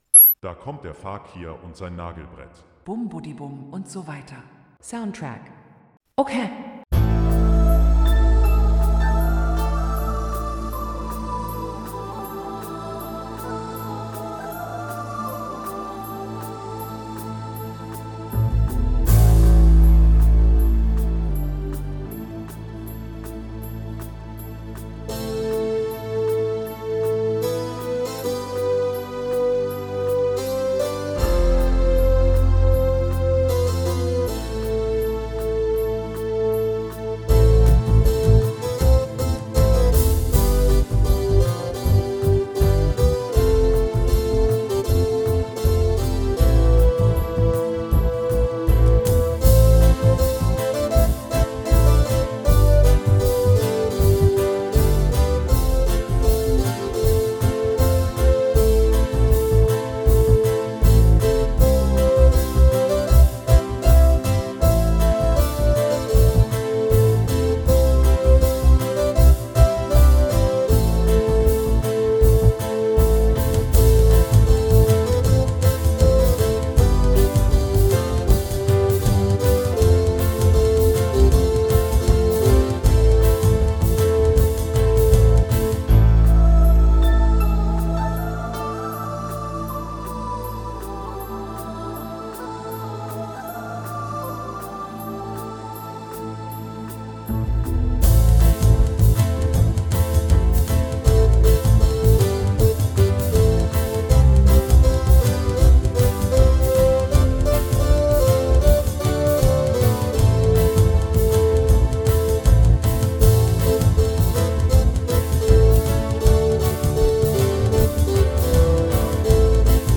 Soundtrack